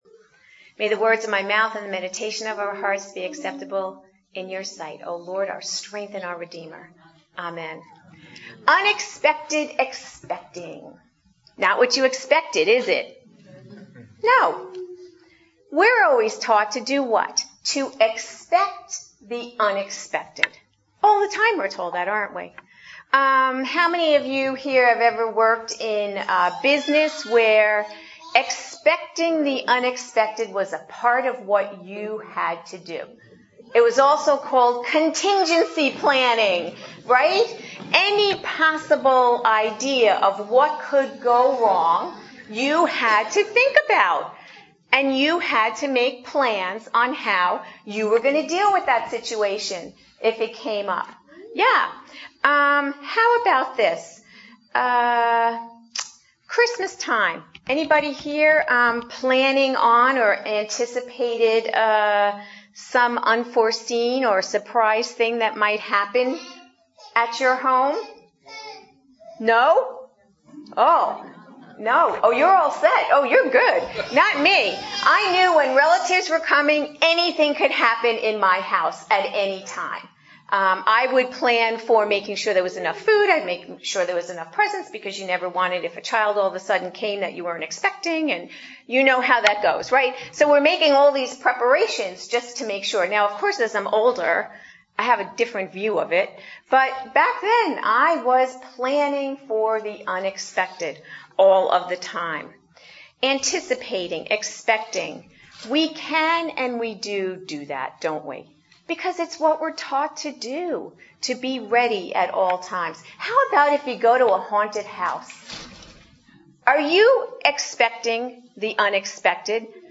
Series: Adult Sermons